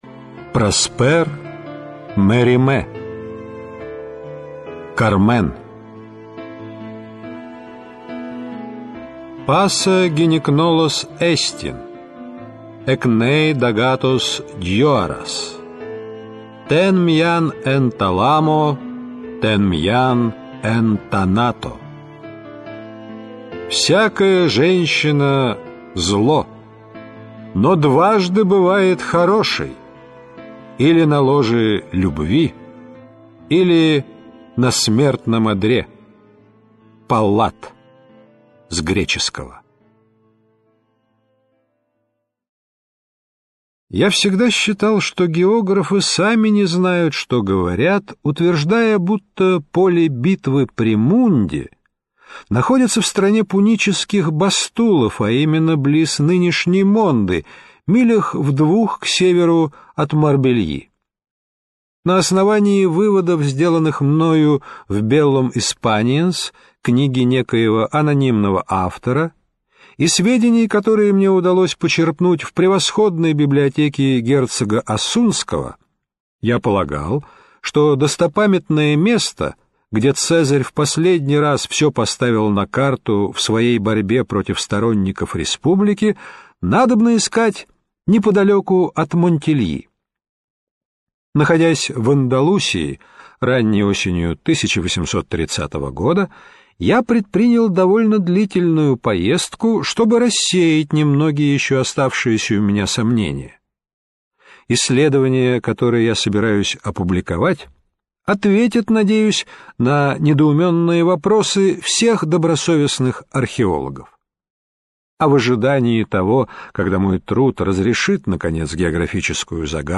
Аудиокнига Кармен. Этрусская ваза | Библиотека аудиокниг